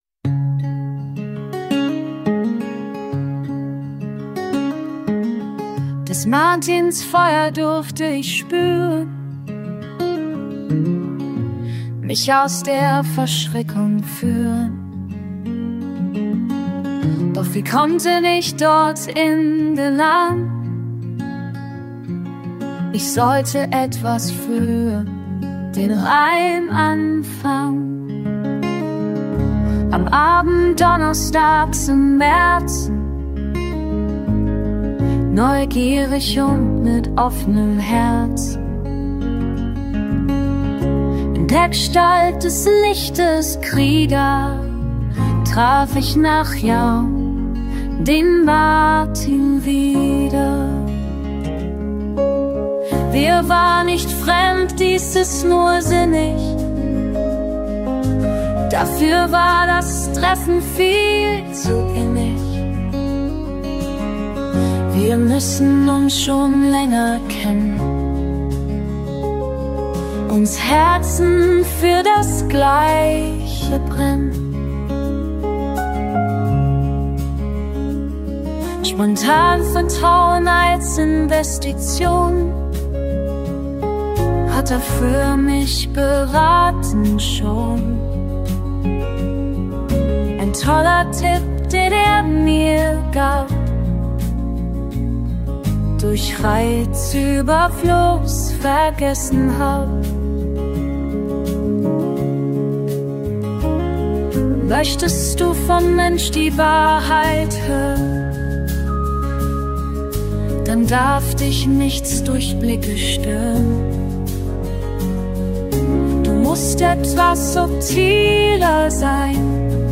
Irish Folk